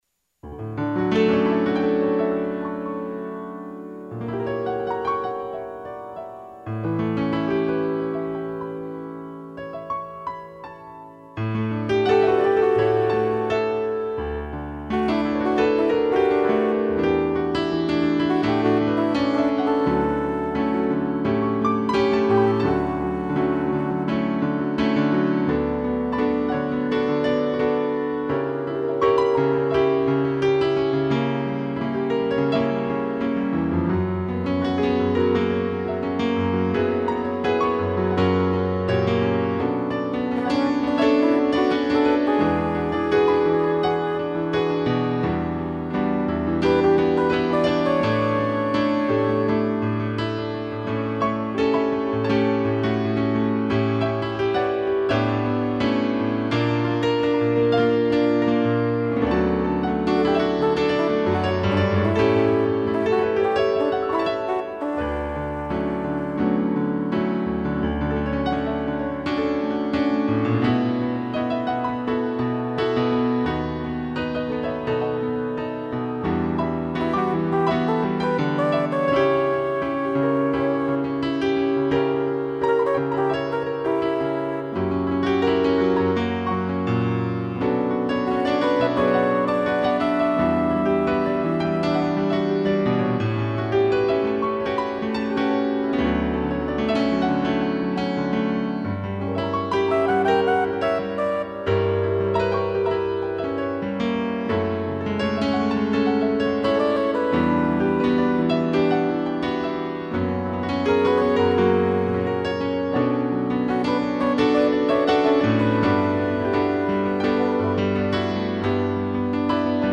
2 pianos e fugel horn